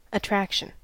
Ääntäminen
Synonyymit charm pull Ääntäminen US US : IPA : /əˈtɹækʃən/ IPA : [əˈtɹækʃ(ɪ̈)n] IPA : [əˈt͡ʃɹækʃ(ɪ̈)n] Haettu sana löytyi näillä lähdekielillä: englanti Käännös Substantiivit 1.